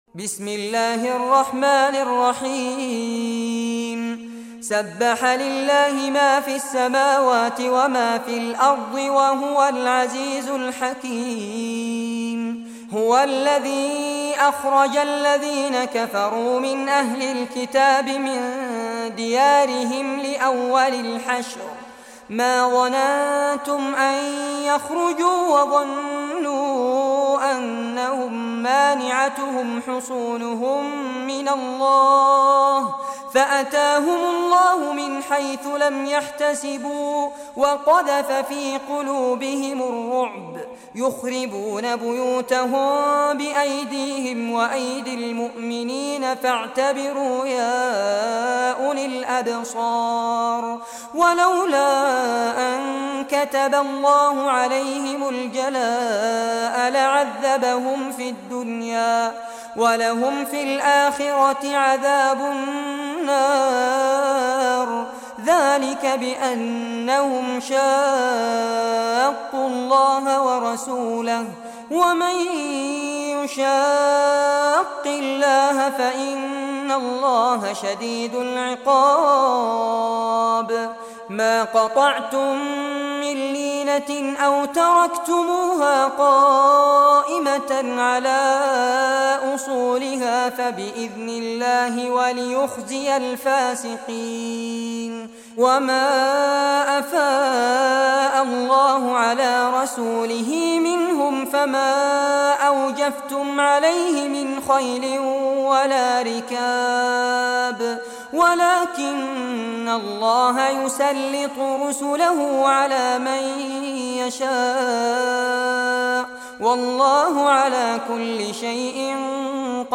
Surah Al-Hashr Recitation by Fares Abbad
Surah Al-Hashr, listen or play online mp3 tilawat / recitation in Arabic in the beautiful voice of Sheikh Fares Abbad.